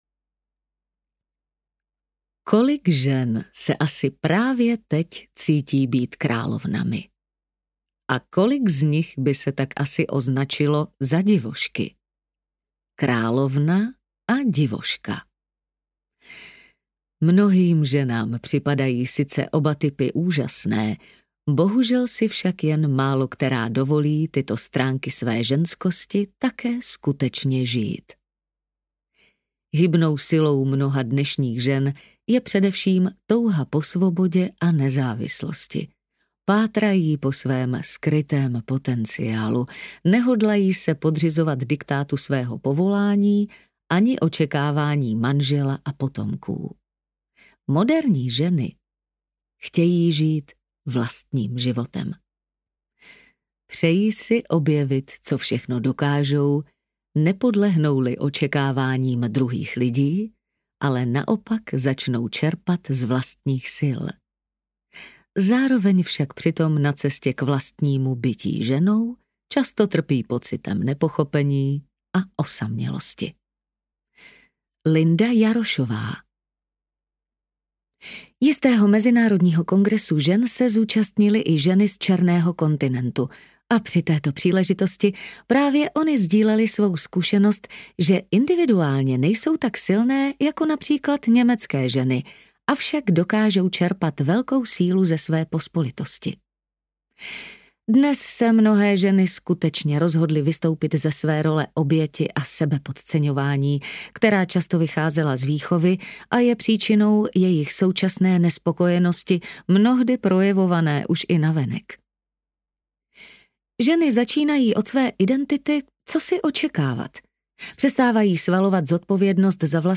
Královna a divoška audiokniha
Ukázka z knihy
kralovna-a-divoska-audiokniha